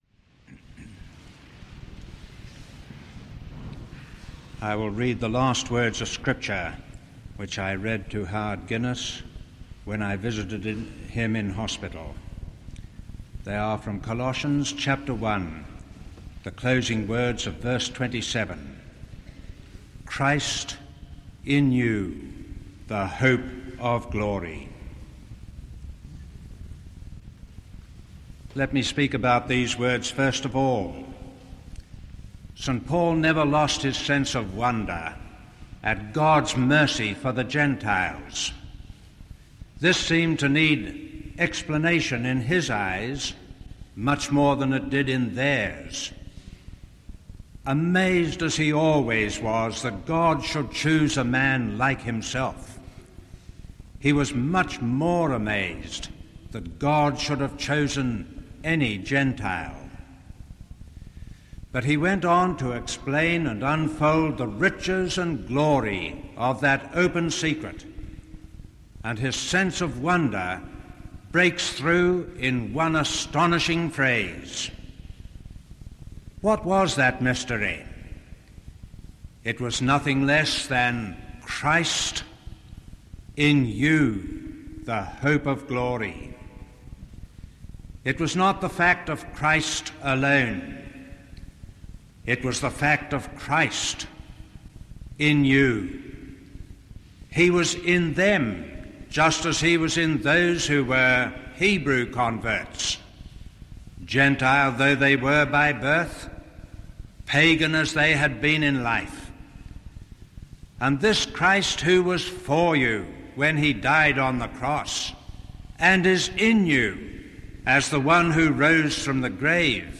Howard Guinness’ funeral was held at St Andrew’s Cathedral, Sydney, on 31st July 1979. Then Archbishop of Sydney, Sir Marcus Loane, preached, and Bishop Donald Robinson led the prayers.
Funeral_of_Howard_Guinness_excerpts_64kbps.mp3